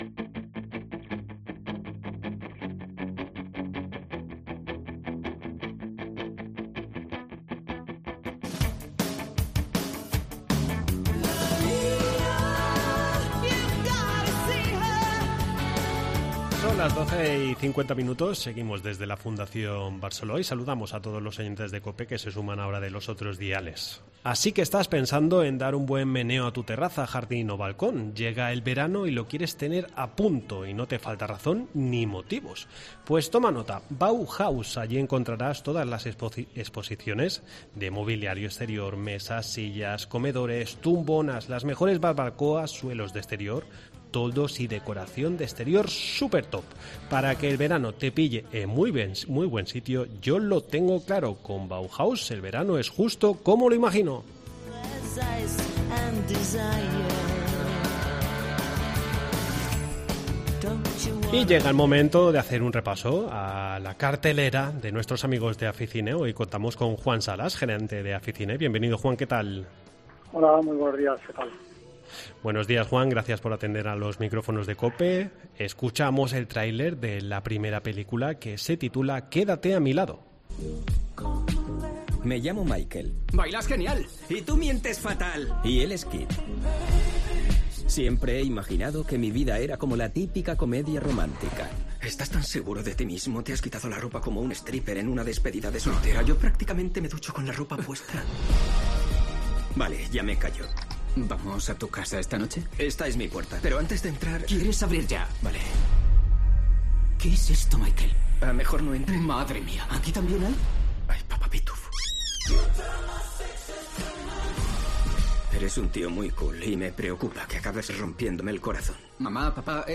. Entrevista en La Mañana en COPE Más Mallorca, viernes 09 junio de 2023.